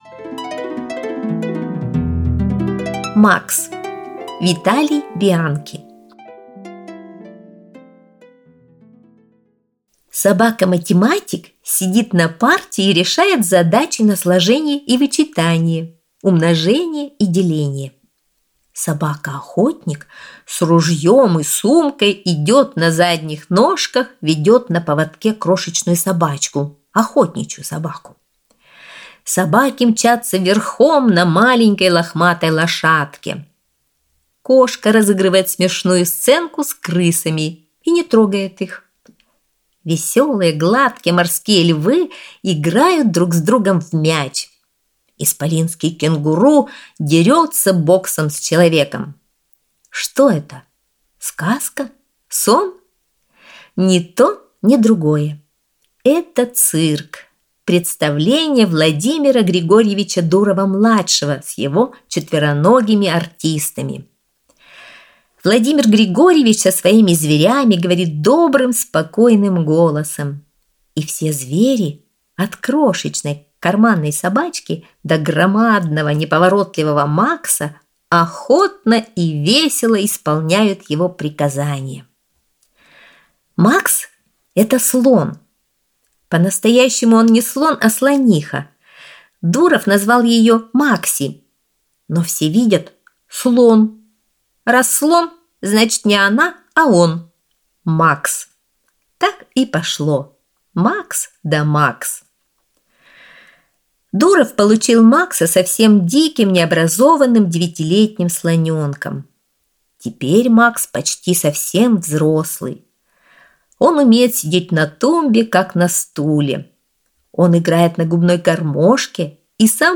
Макс - аудио рассказ Бианки - слушать онлайн